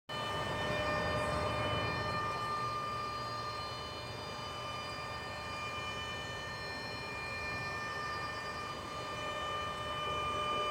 Sirenengeheule.
Im Soundschnipsel heult eine nervige Sirene durchgängig, es sind ein paar Stadtgeräusche, Autos und Blätterrascheln mit dabei. a5a7d1e2cf86e040.mp3 1